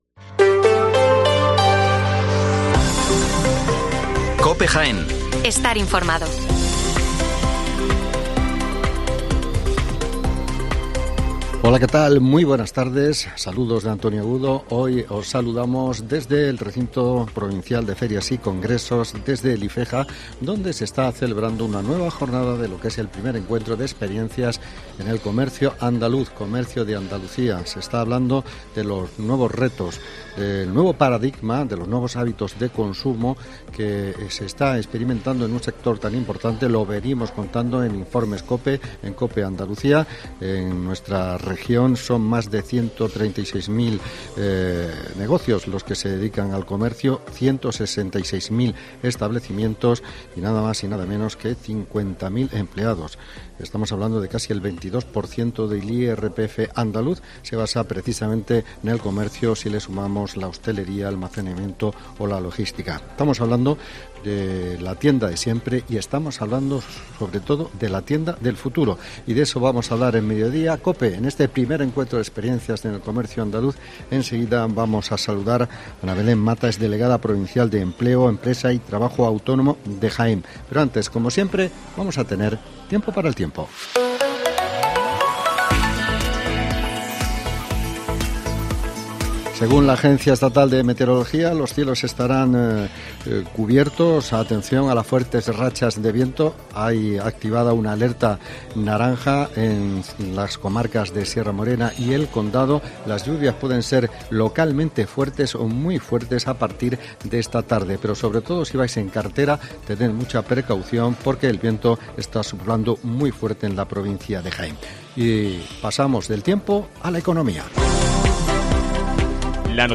Informativo Mediodía COPE 14:20 horas